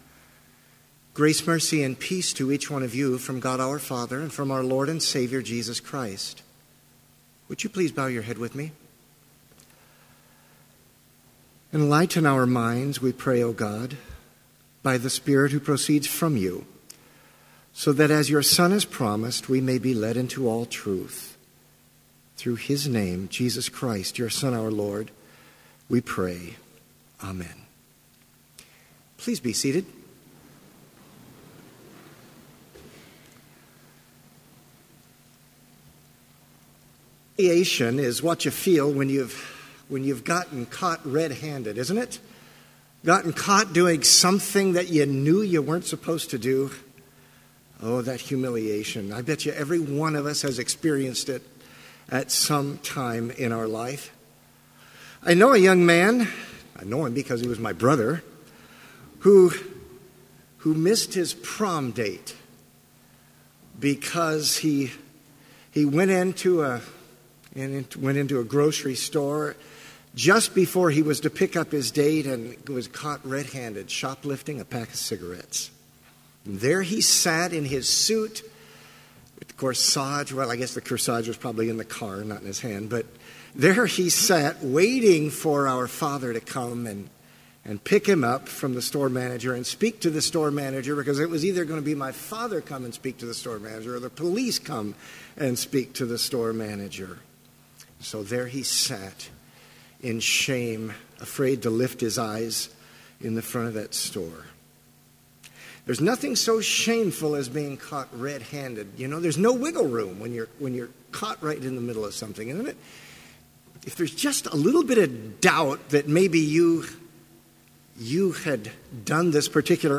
Chapel worship service held on October 5, 2016, BLC Trinity Chapel, Mankato, Minnesota, (video and audio available)
Complete service audio for Chapel - October 5, 2016